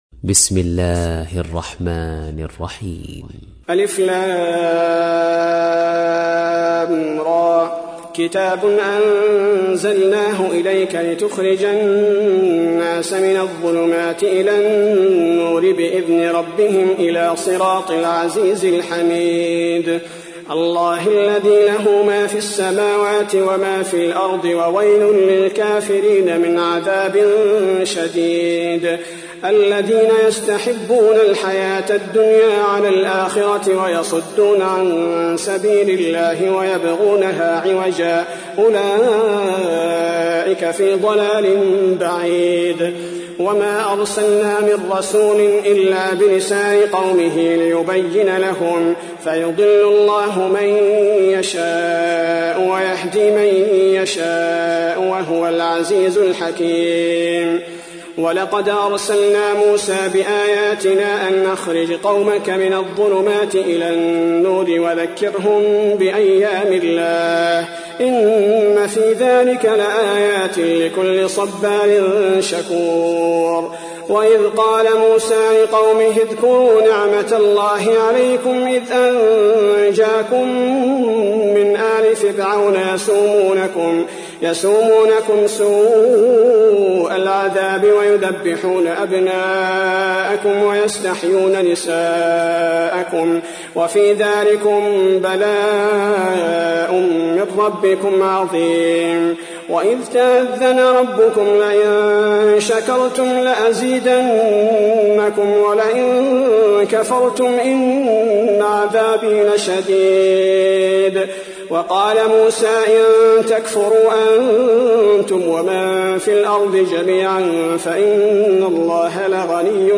تحميل : 14. سورة إبراهيم / القارئ عبد البارئ الثبيتي / القرآن الكريم / موقع يا حسين